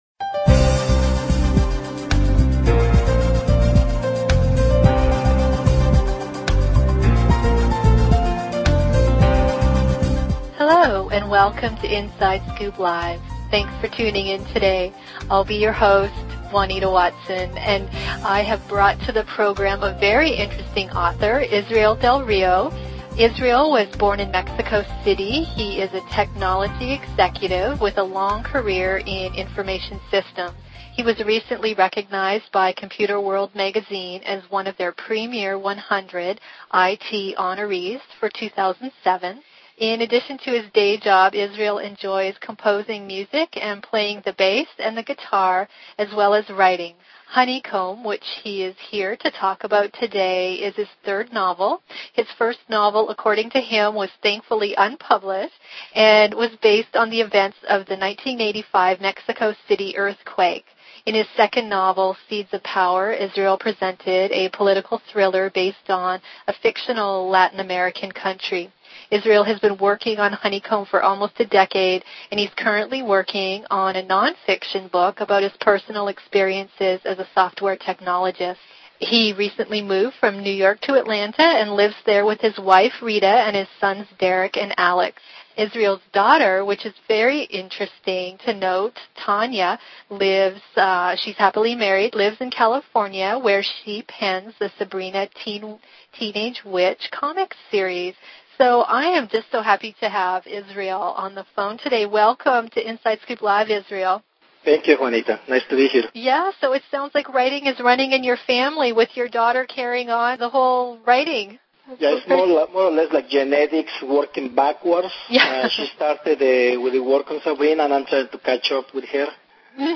audioInterview.mp3